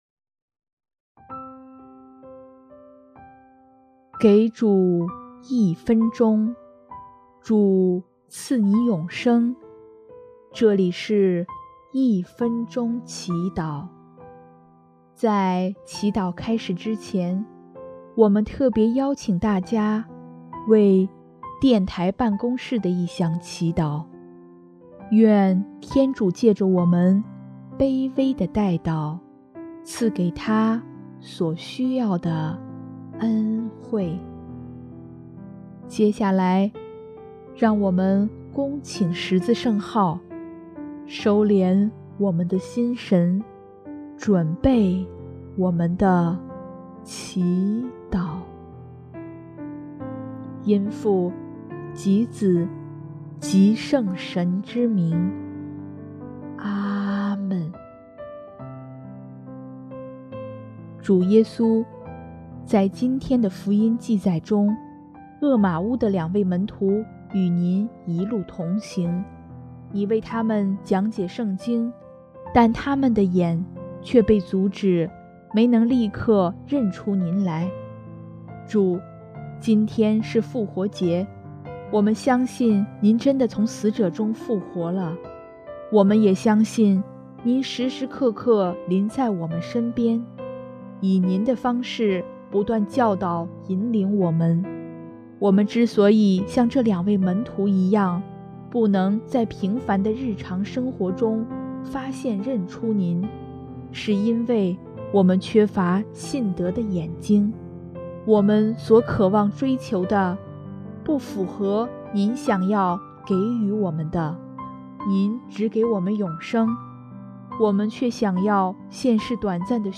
音乐：第一届华语圣歌大赛参赛歌曲《逾越节的羔羊》（电台办公室：为大赛的顺利推广）